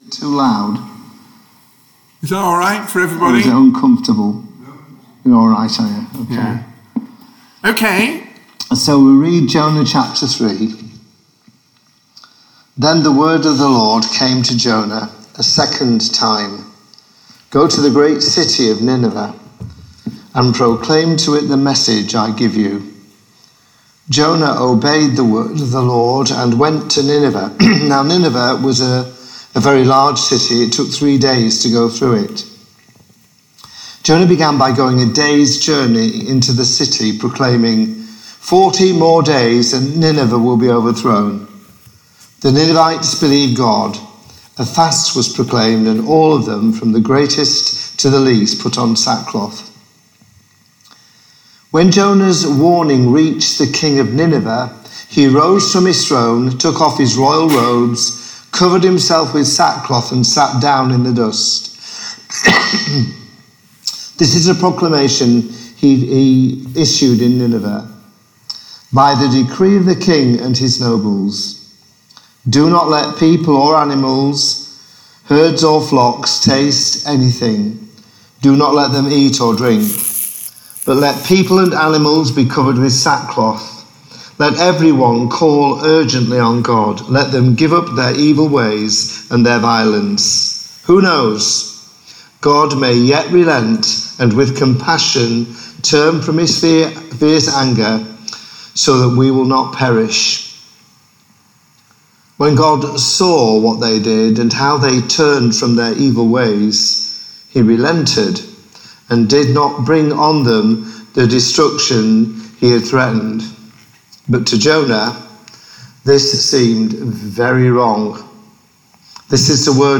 The Message: � Jonah Chapter 3 If you have a Bible to hand please open it at Jonah chapter 3 .